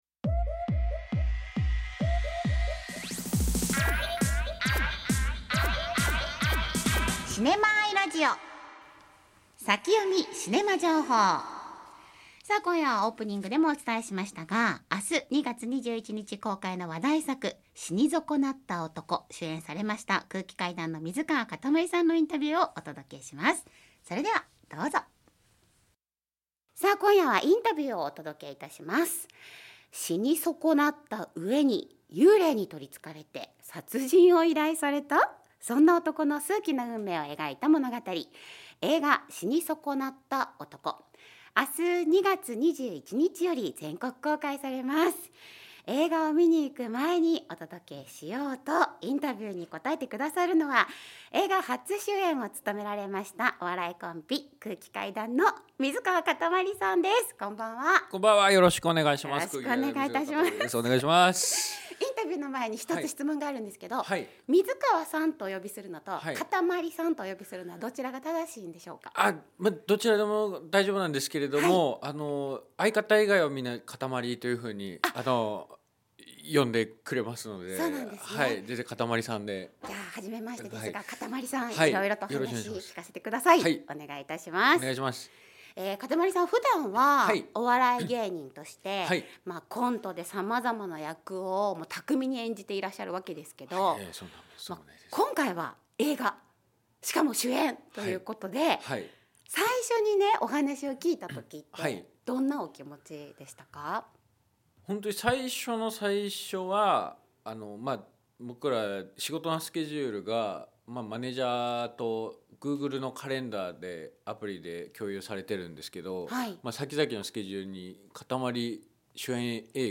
映画初主演の水川かたまりさんにスペシャルインタビュー！